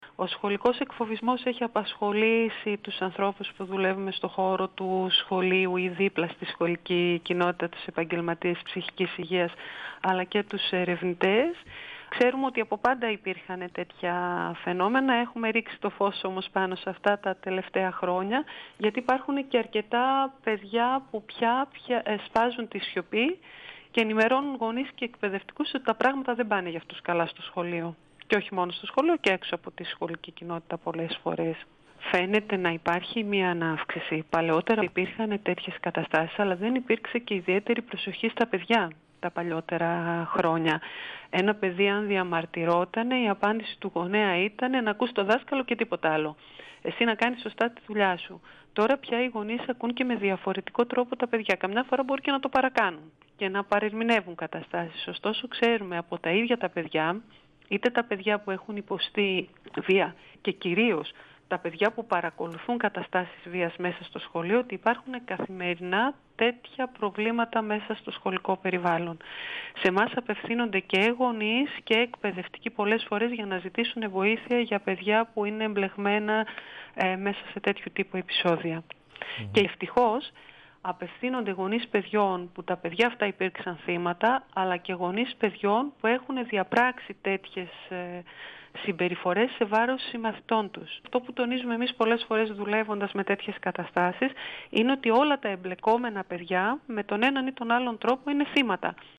Συνέντευξη